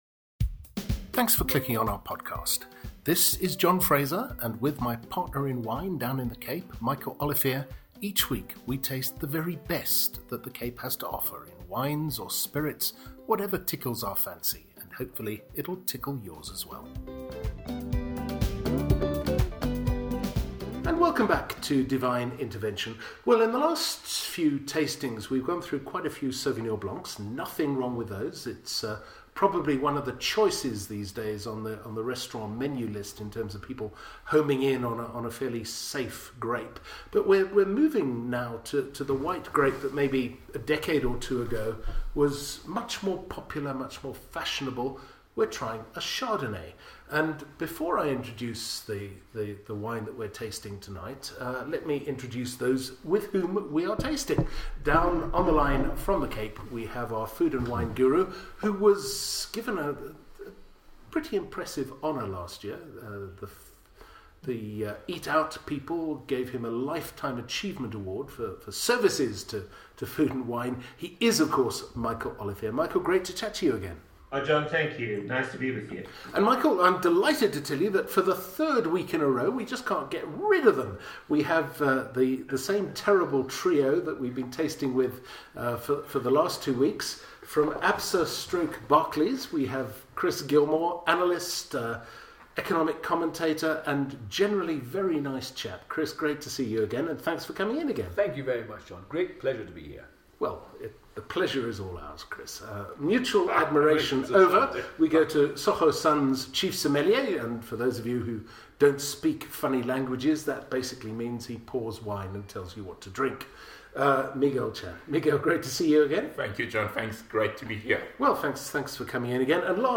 to keep order in the Johannesburg studio